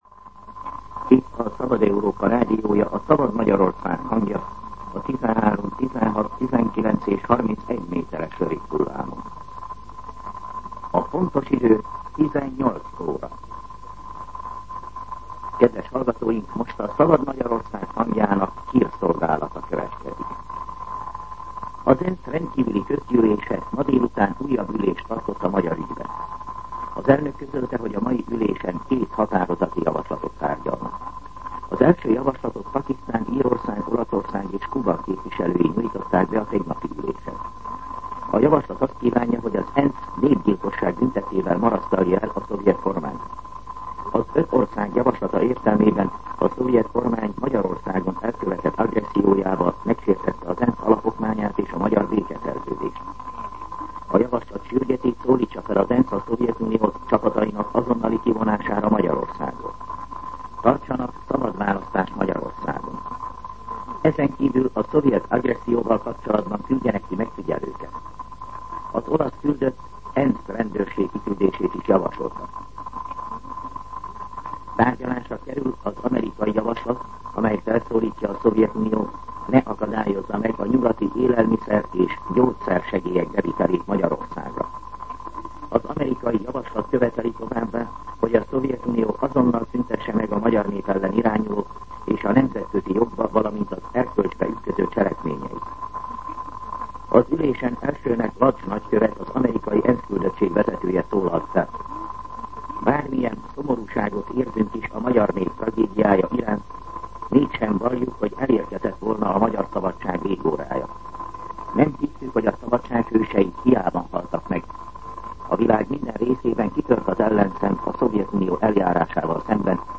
18:00 óra. Hírszolgálat